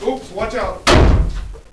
Effetto sonoro - Attenzione !
Voce in inglese che dice "Oops watch out !" e rumore di porta che viene chiusa con forza.